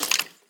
Sound / Minecraft / mob / skeleton / step3.ogg
step3.ogg